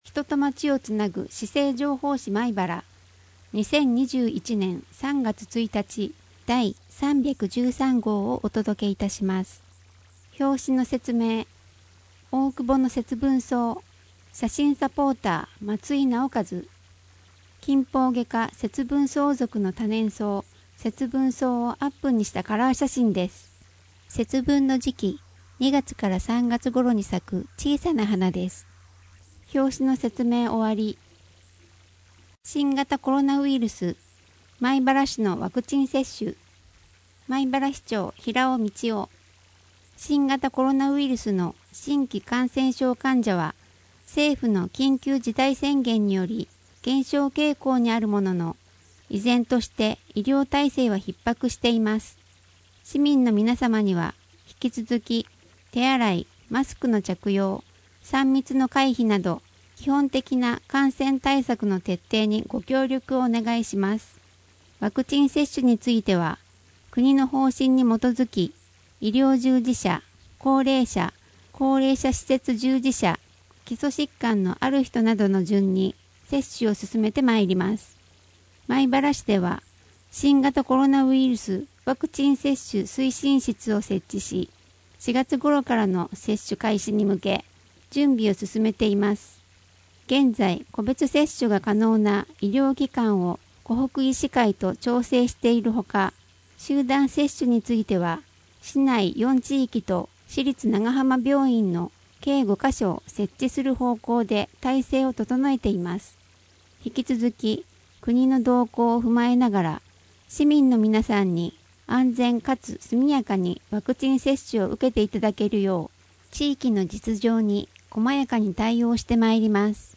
視覚障がい者用に広報まいばらを音訳した音声データを掲載しています。 音声データは音訳グループのみなさんにご協力いただき作成しています。